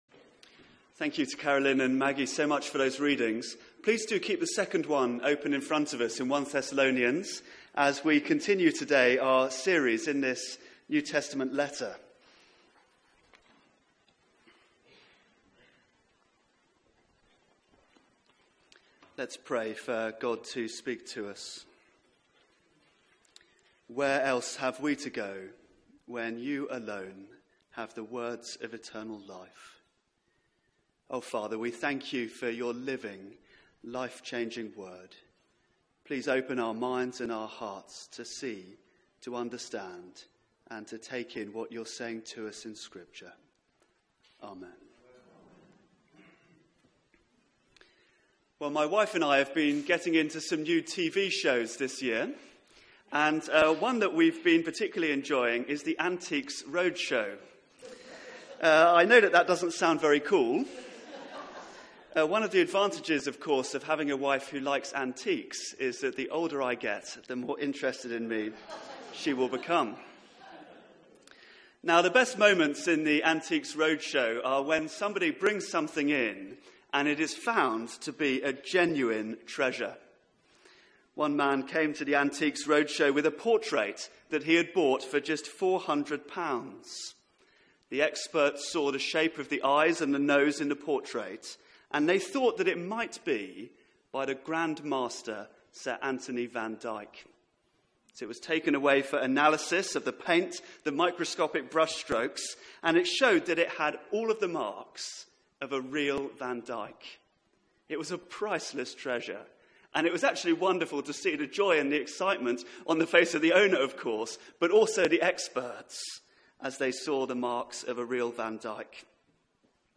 Media for 9:15am Service on Sun 21st May 2017 09:15 Speaker
Theme: Real Christian Believers Sermon